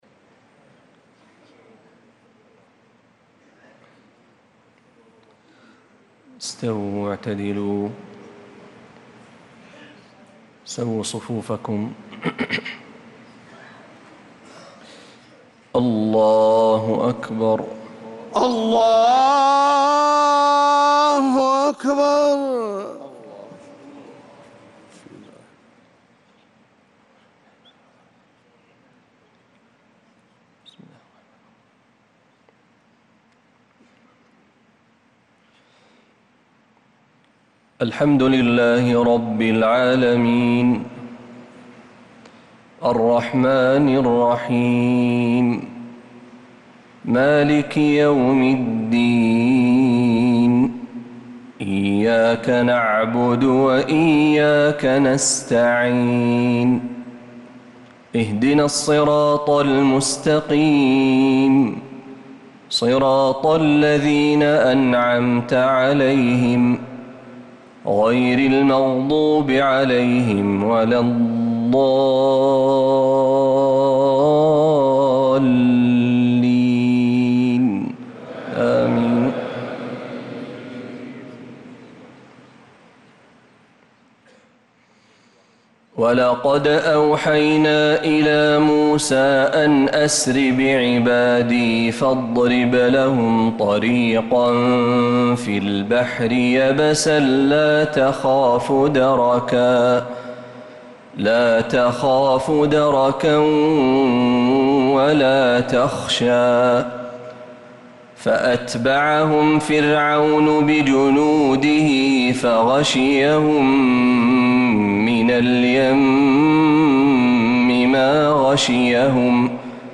صلاة العشاء للقارئ محمد برهجي 6 ربيع الآخر 1446 هـ